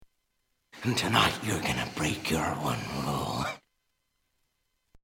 Als Schmankerl gab’s ein Sprachsample vom Joker: